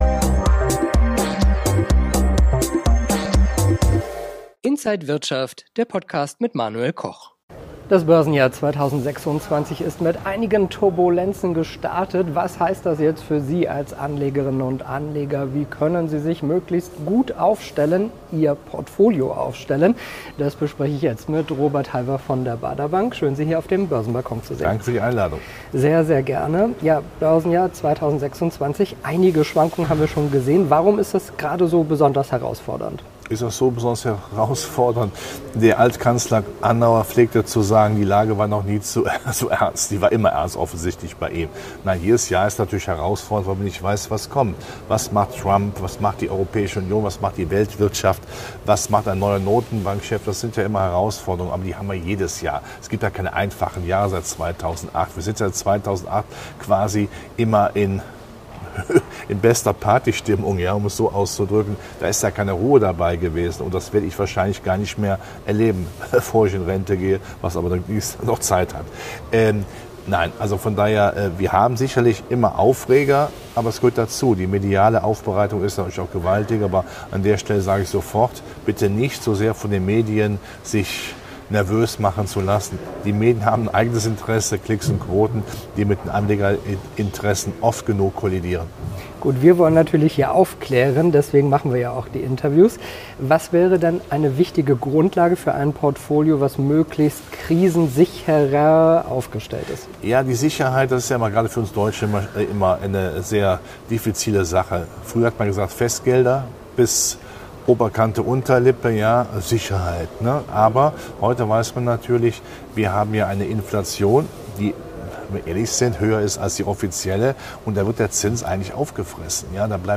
Alle Details im Interview von